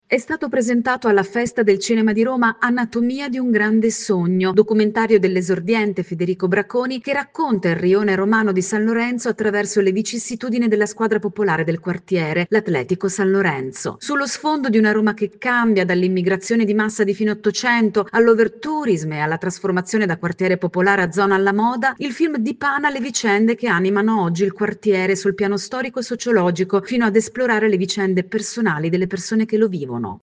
Anatomia di un grande sogno – Alla Festa del Cinema di Roma il documentario sul rione San Lorenzo. Il servizio